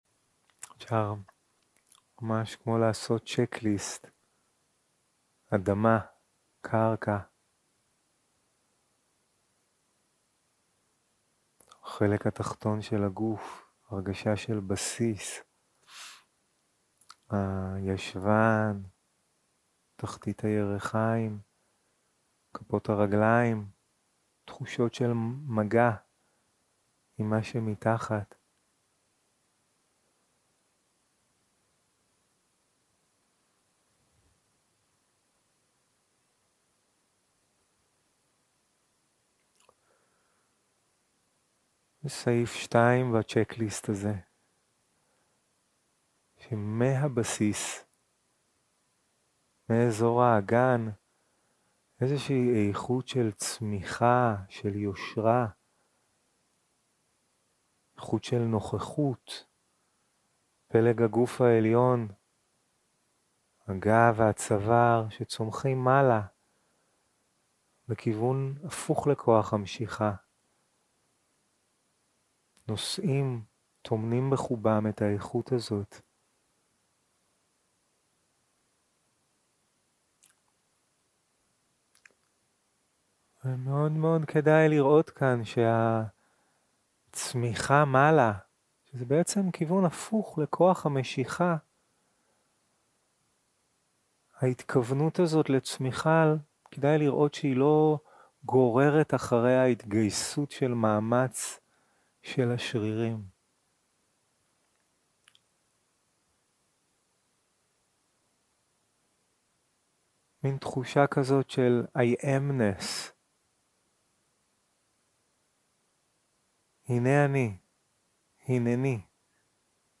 יום 3 - הקלטה 6 - צהרים - מדיטציה מונחית
Dharma type: Guided meditation